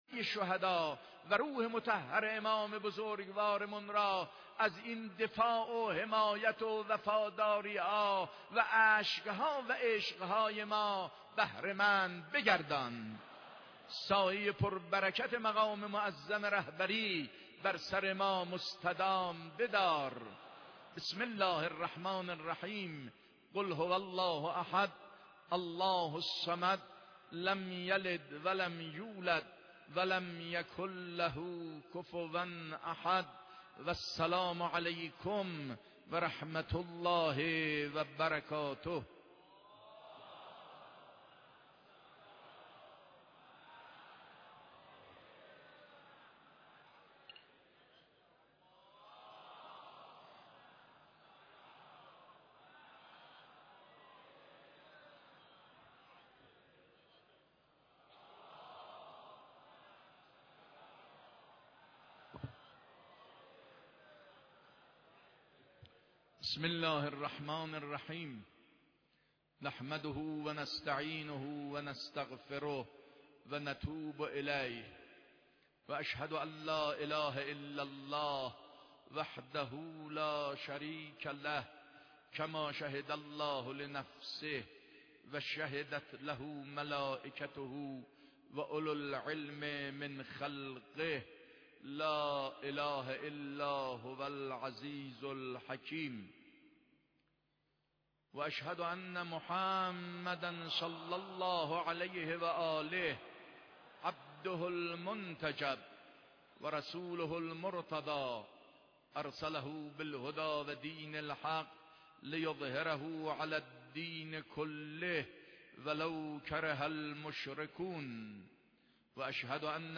خطبه دوم 3 مرداد.mp3